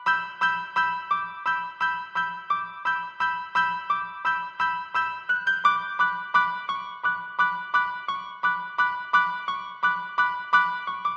Tag: 86 bpm Hip Hop Loops Piano Loops 1.88 MB wav Key : Unknown